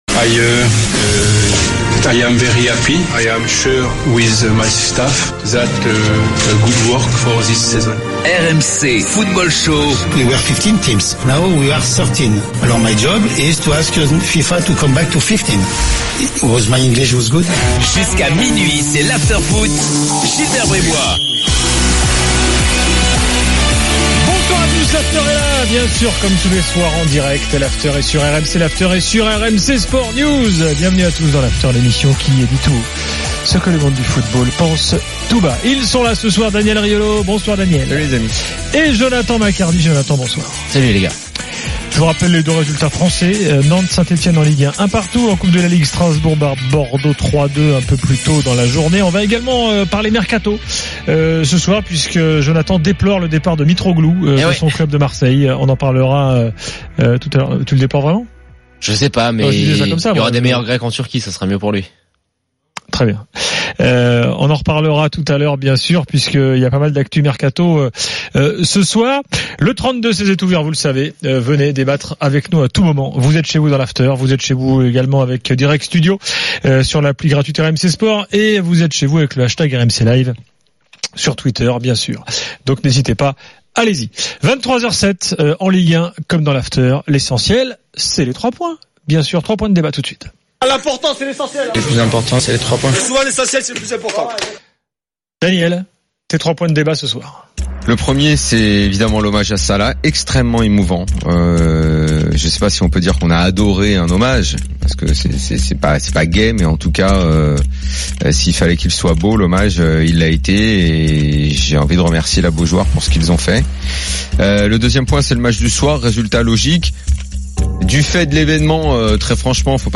le micro de RMC est à vous !